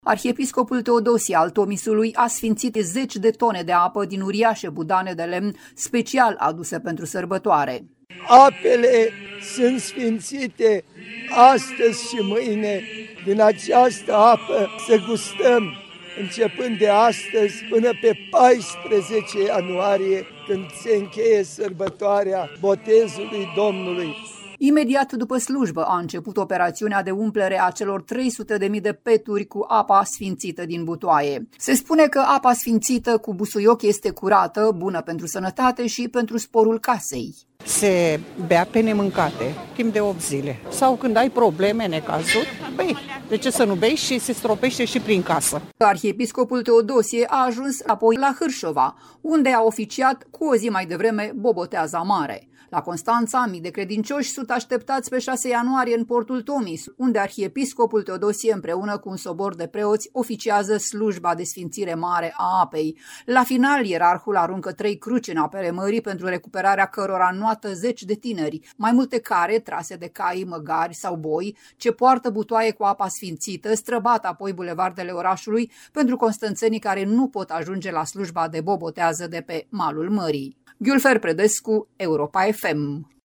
Arhiepiscopul Teodosie al Tomisului a sfințit zeci de tone de apă din uriașe budane de lemn special aduse pentru sărbătoare.
„Apele sunt sfințite astăzi și mâine. Din această apă să gustăm, începând de astăzi până pe 14 anuarie, când se încheie sărbătoarea botezului Domnului”, a rostit Arhiepiscopul Teodosie al Tomisului.
„Se bea pe nemâncate timp de opt zile. Sau când ai probleme, necazuri, bei, de ce să nu bei și se stropește și prin casă”, a spus o femeie.